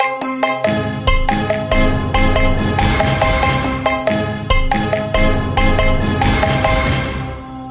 Вот мой самый первый луп в жизни. Использовал 8 перкуссионных инструментов. Так же присутствуют щелчки пальцами и хит органа. smile